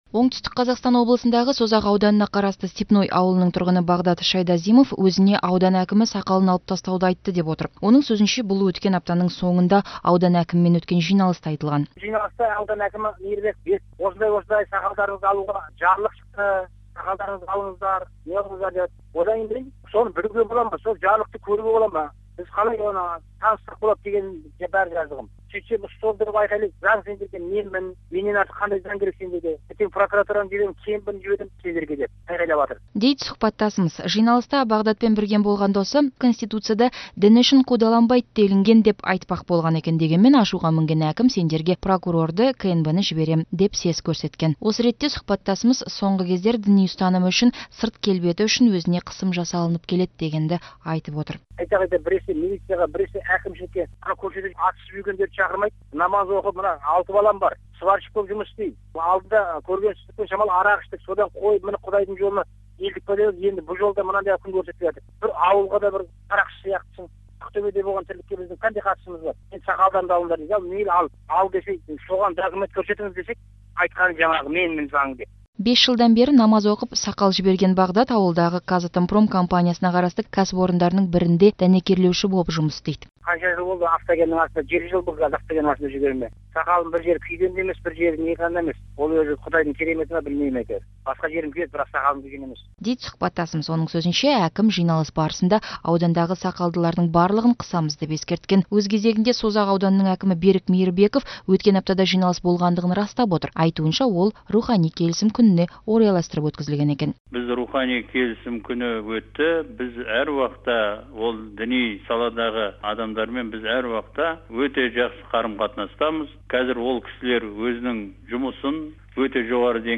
Созақтағы сақал дауына байланысты радиохабар